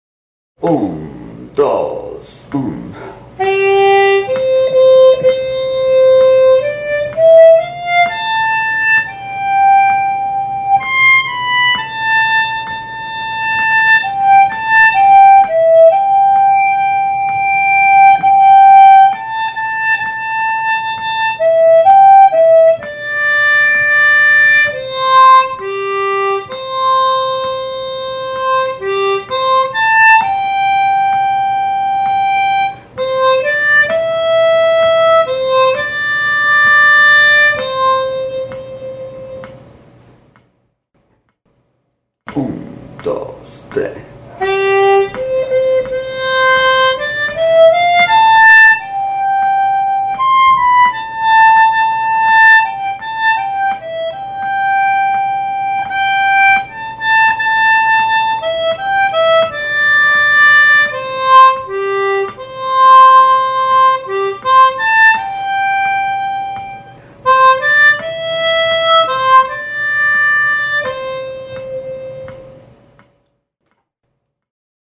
Los temas están por orden de dificultad y los archivos de audio tienen cada uno su versión lenta de práctica y la versión al tempo original más rápido.
Todos los temas están tocados con armónica diatónica de 10 celdas afinada en Do (letra C).